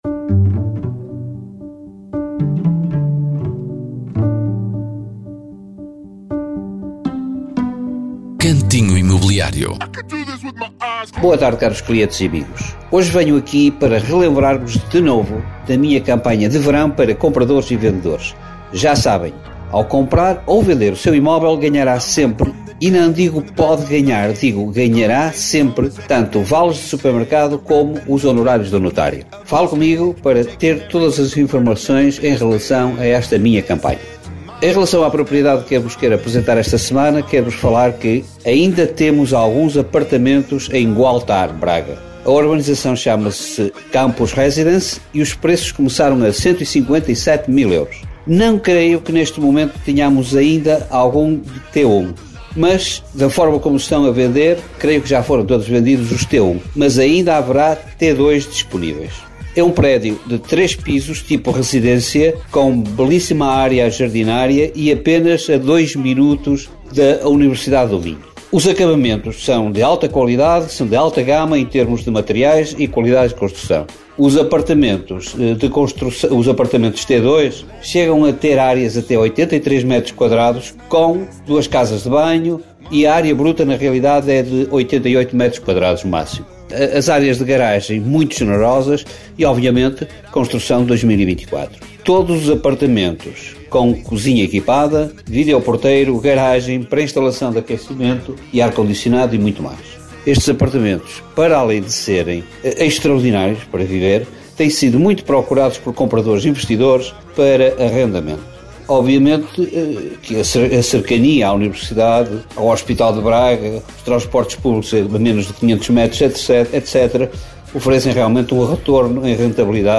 Cantinho Imobiliário é uma rubrica semanal da Rádio Caminha sobre o mercado imobiliário.